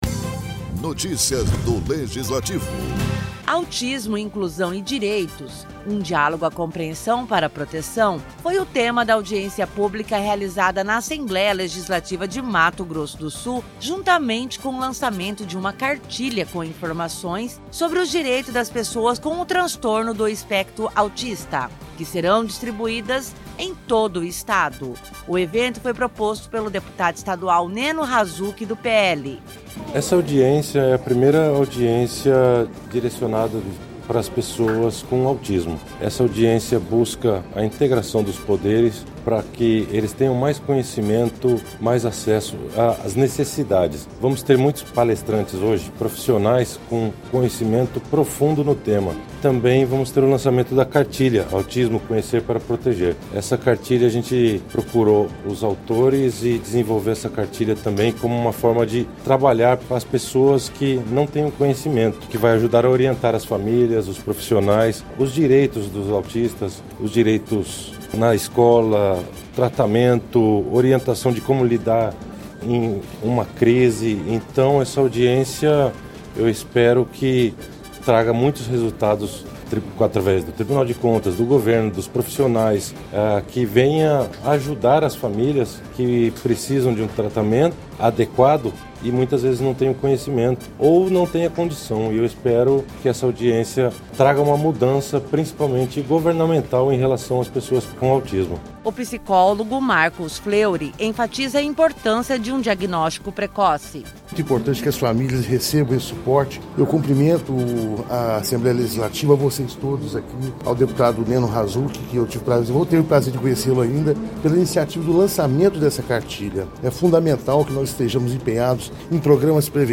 Durante a audiência pública realizada na Assembleia Legislativa de Mato Grosso do Sul, com o tema “Autismo, Inclusão e Direitos: Um Diálogo à Compreensão para Proteção”, foi realizado o lançamento de uma cartilha com informações sobre os direitos das pessoas com o transtorno do Espectro Autista, que serão distribuídas em todo o Estado.O evento proposto pelo deputado Neno Razuk, do PL e realizado no Plenário Deputado Júlio Maia.